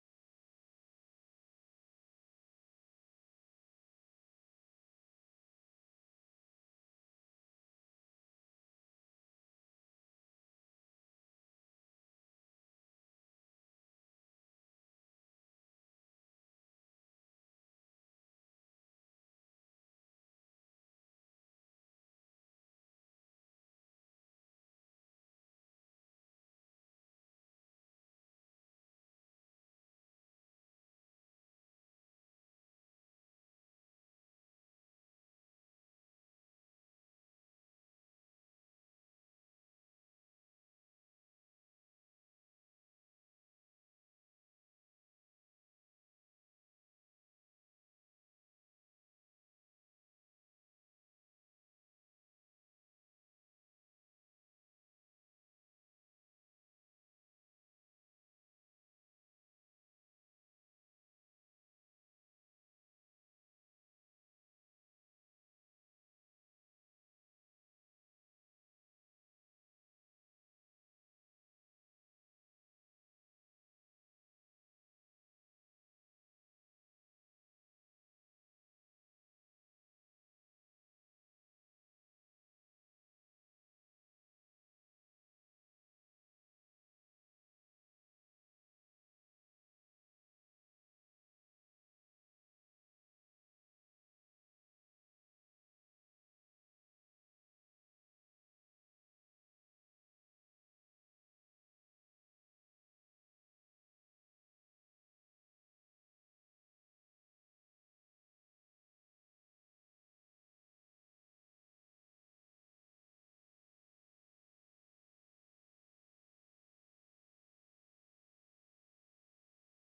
Sermons | Scranton Road Bible Church